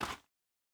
Bare Step Gravel Hard A.wav